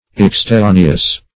Search Result for " exterraneous" : The Collaborative International Dictionary of English v.0.48: Exterraneous \Ex`ter*ra"ne*ous\, a. [L. exterraneus; es out + terra land.] Foreign; belonging to, or coming from, abroad.